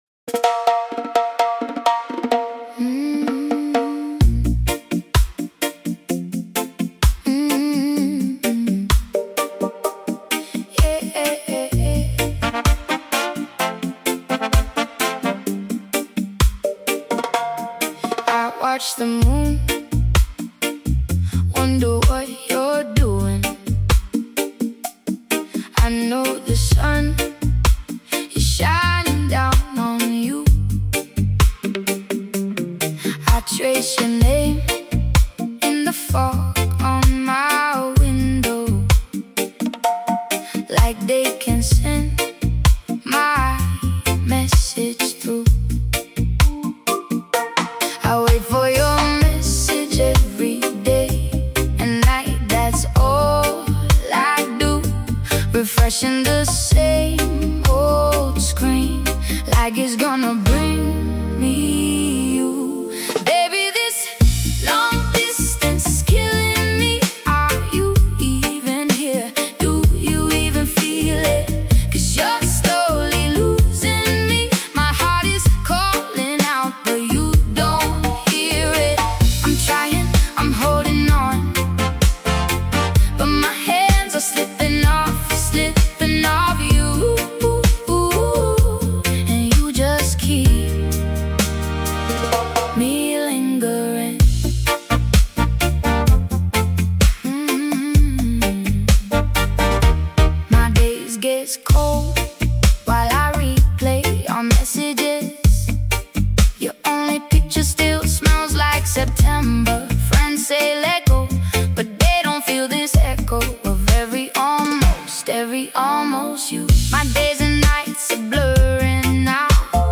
The pacing gives everything room to settle in naturally.